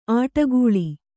♪ āṭagūḷi